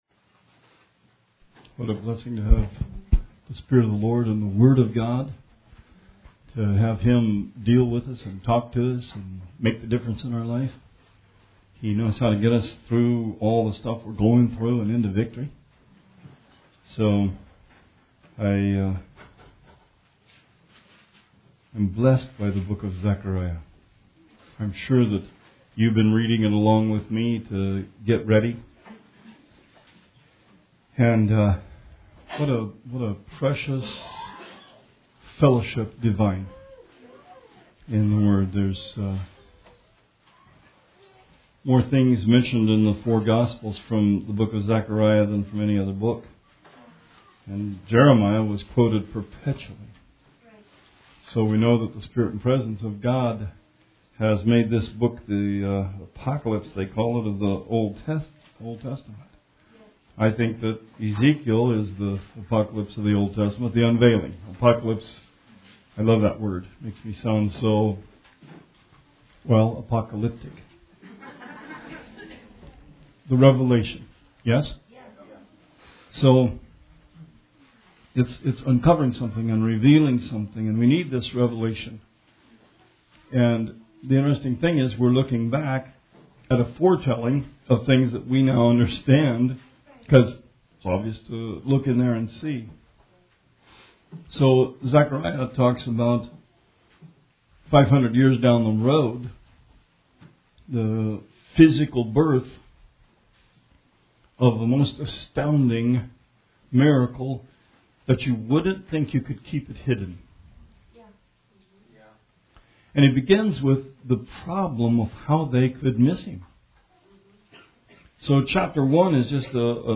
Sermon 1/14/18